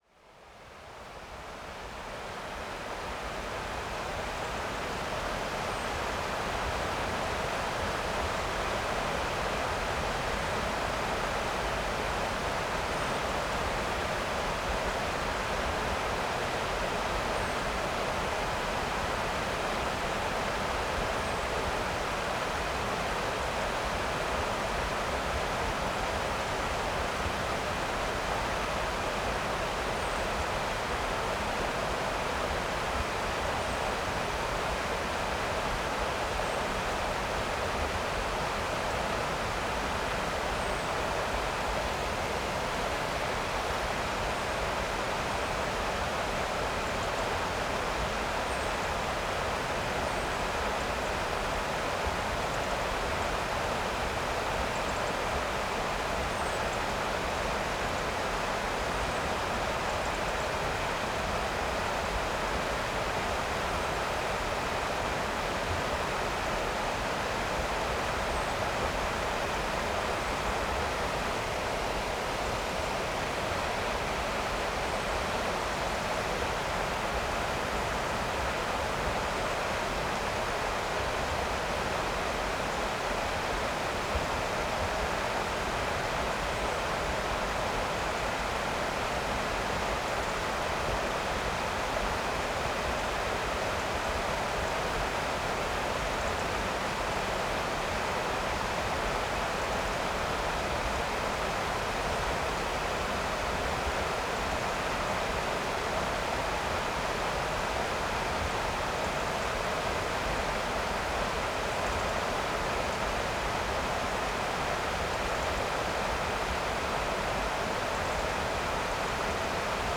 Summer_Mountains_Stream_Big_Forest_Distant_Perspective.wav